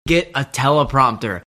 Now here’s a hard attack after final /t/, in get a teleprompter:
He makes a visible tongue articulation for the final /t/ of get at 0:12, so this is ge[t ʔ]a teleprompter.